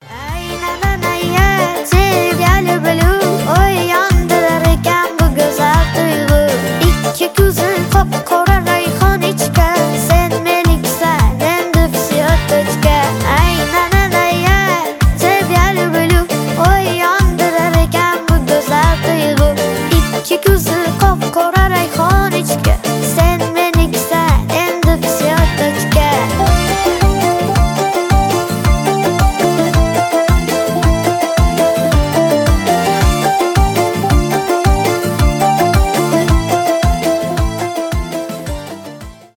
Узбекские
Поп